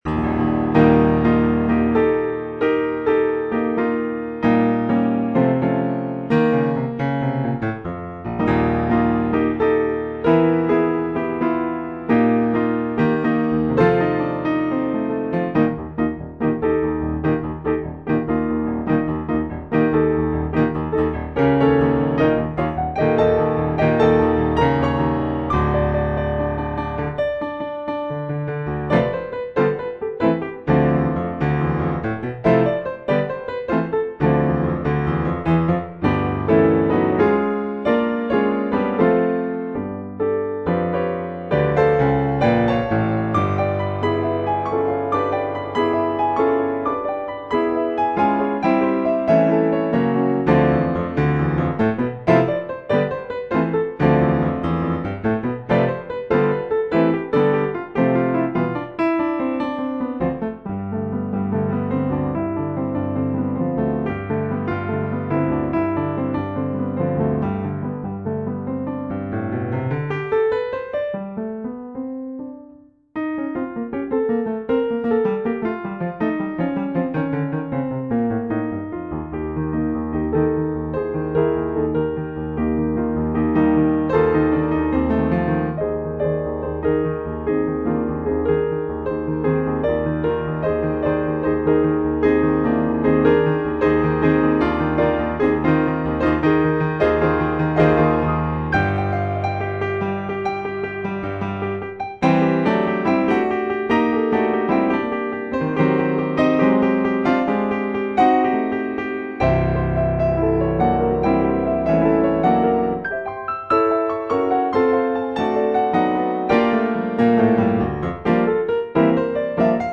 Here are recordings (mp3 files) in which I play eight of my piano compositions.
Celebration was composed in 2012, the year of the Queen's Diamond Jubilee and of the London Olympic Games. Something of these joyous events may be felt in the music.